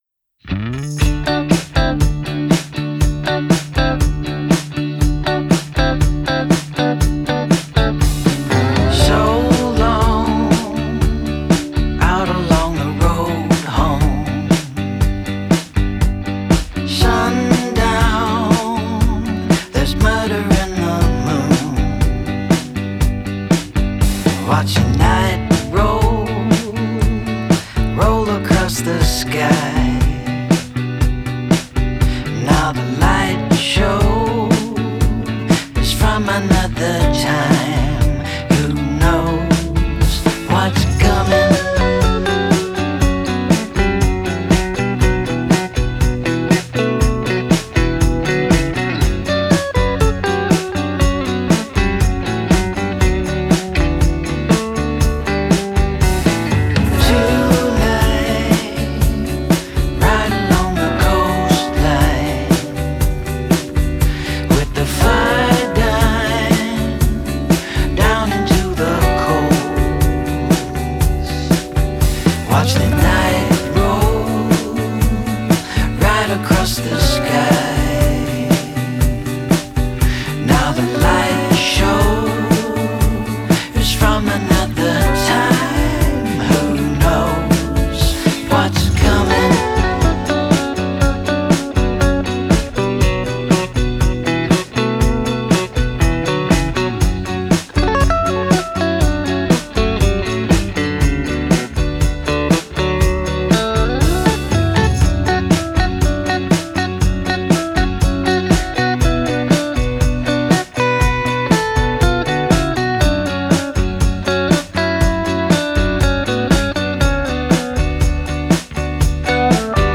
Uploading a new mix of Another Time.
The 2nd version kind of survives the soundcloud playback better.
I started noticing how much mud was in tracks that were supposed to be panned to the sides.